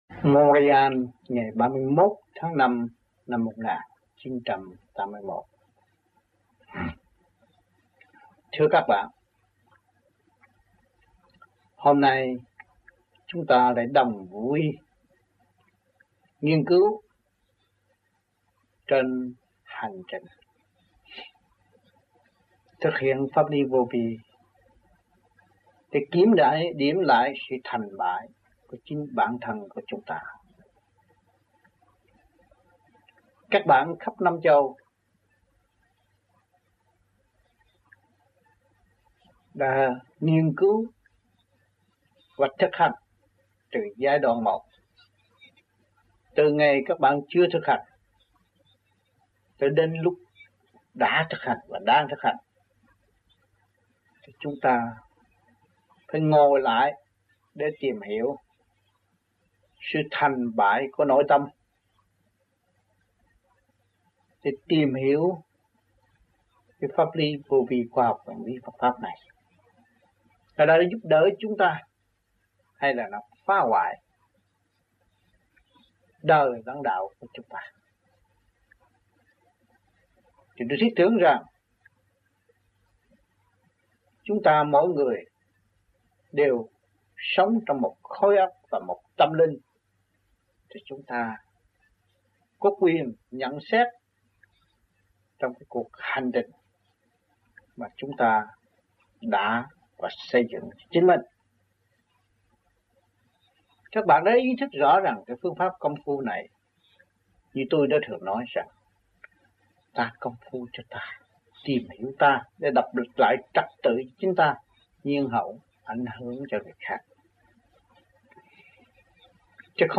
Canada Trong dịp : Sinh hoạt thiền đường >> wide display >> Downloads